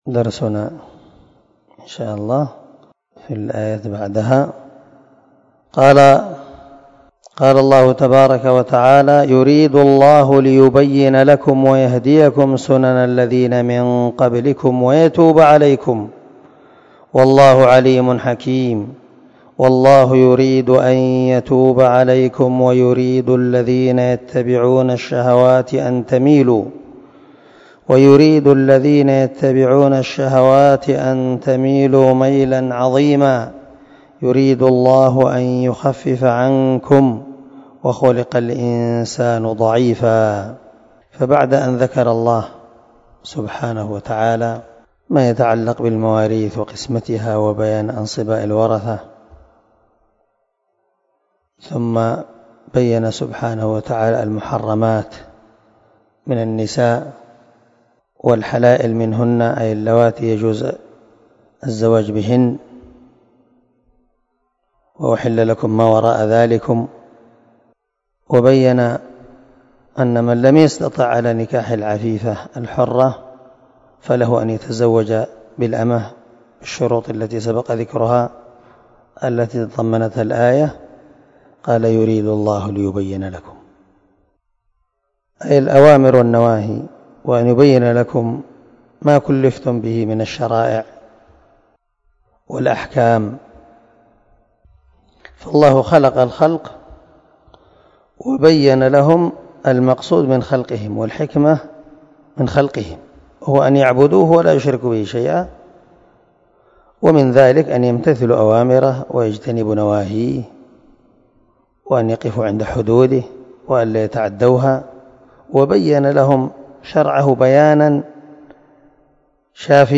255الدرس 23 تفسير آية ( 26 – 28 ) من سورة النساء من تفسير القران الكريم مع قراءة لتفسير السعدي
دار الحديث- المَحاوِلة- الصبيحة.